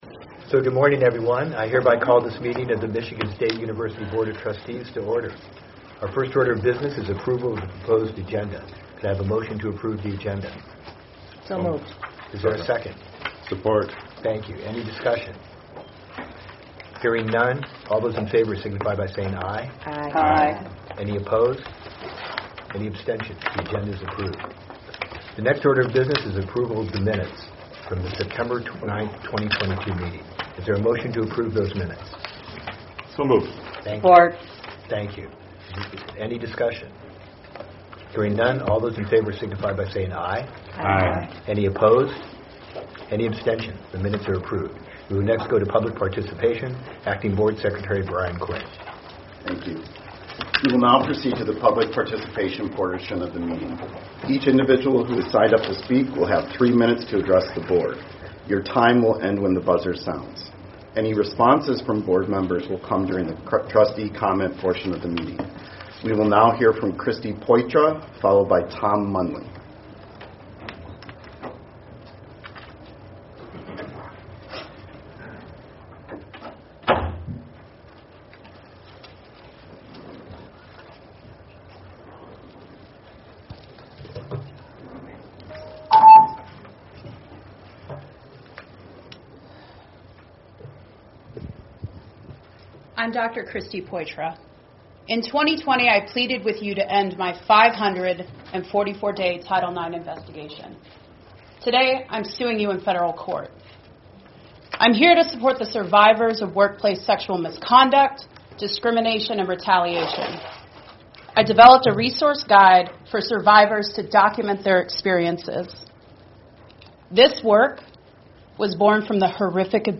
Where: Board Room, 401 Hannah Administration Building